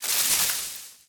bushes2.ogg